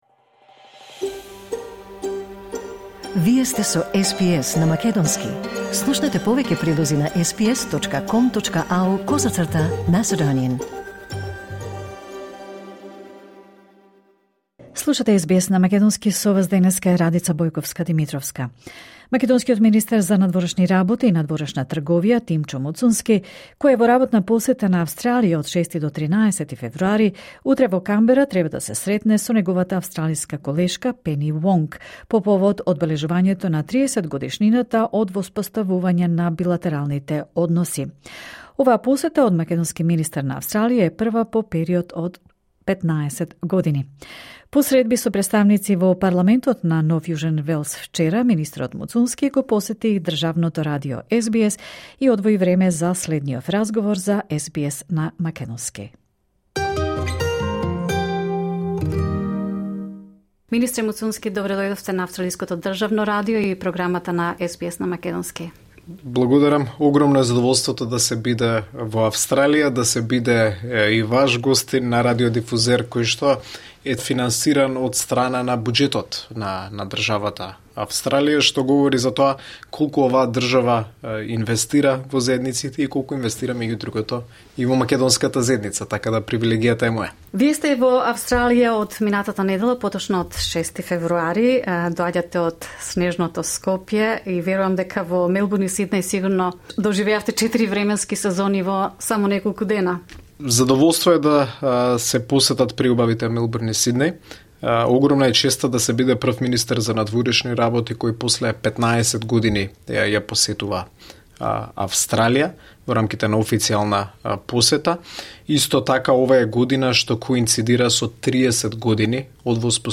Македонскиот министер за надворешни работи и надворешна трговија Тимчо Муцунски, кој е во работна посета на Австралија по повод 30-годишниот јубилеј од воспоставувањето на билатералните односи, го посети државното радио СБС за ексклузивно интервју на програмата на македонски јазик.
Македонскиот министер за надворешни работи и надворешна трговија Тимчо Муцунски во студио на австралиското државно радио СБС, 10 февруари 2025, Сиднеј.